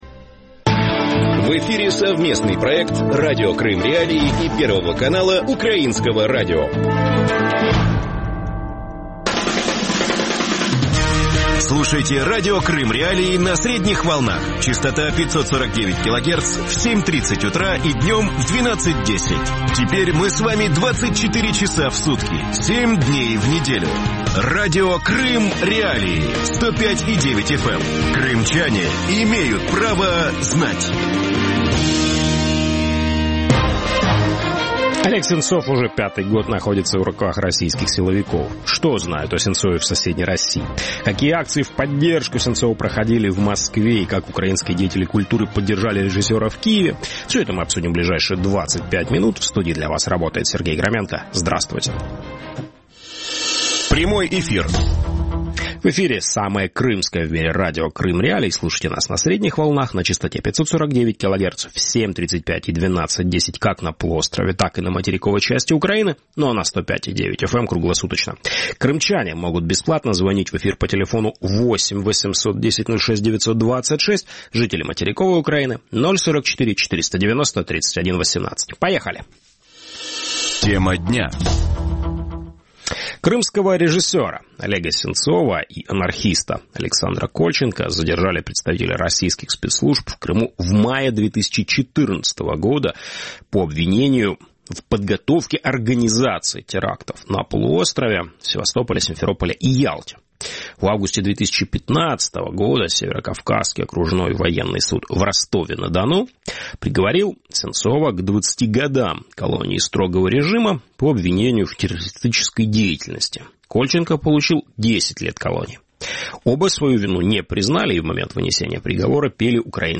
Есть ли надежда на освобождение? Гости эфира: